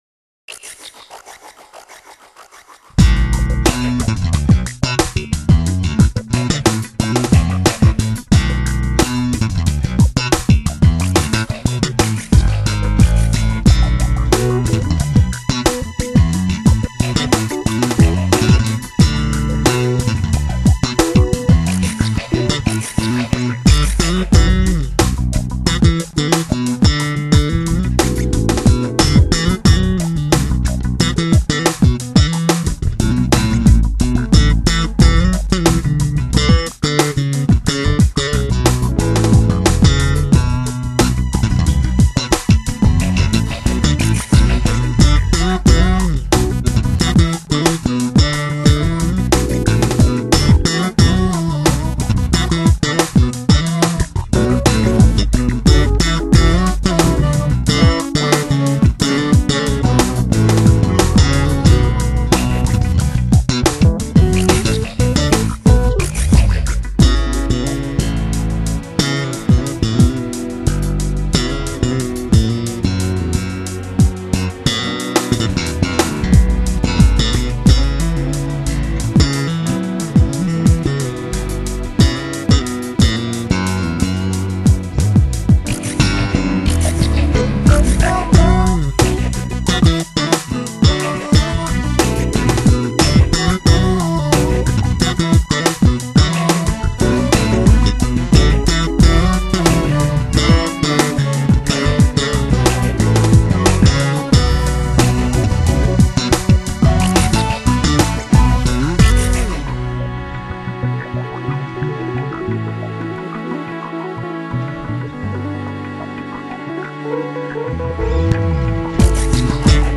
谁能将这里丰满有肉、弹性迷人的低频播得乾净快速，毫不失控，谁就有一套令人惊羡的好音响。